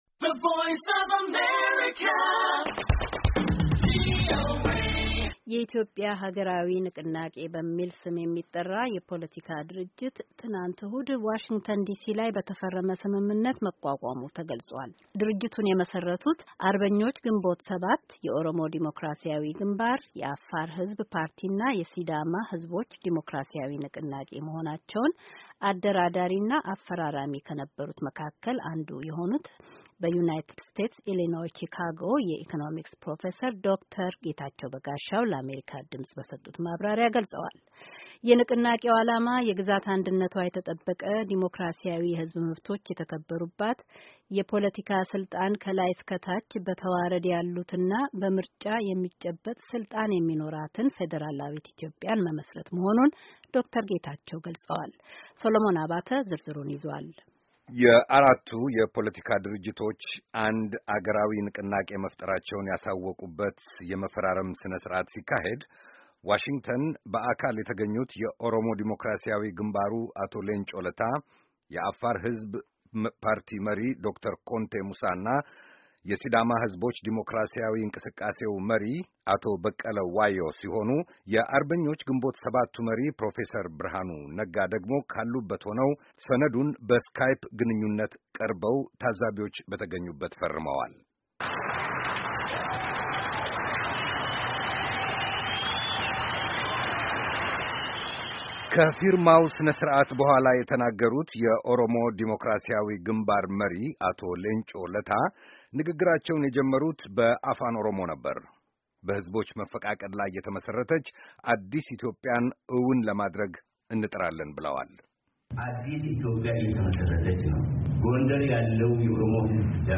ከቪኦኤ ጋር ያደረጉት ቃለ ምልልስ